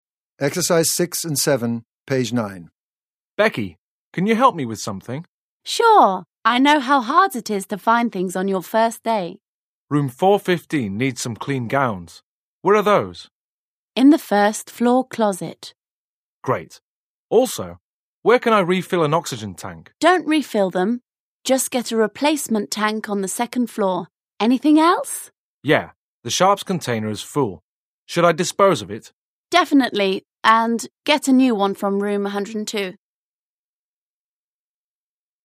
Организует работу над диалогом (аудирование).